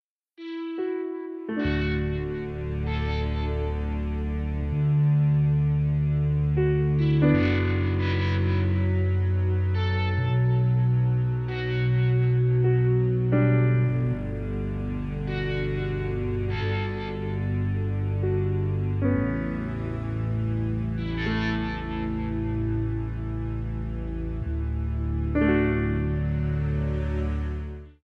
The first piece is for a melancholic mood that as a theme I can use for different characters and different parts of the movie. Have a listen to the excerpt below, and as with last week’s theme, it is a very rough, unpractised piece that has not been cleaned up or mixed well.
melancholic-theme.m4a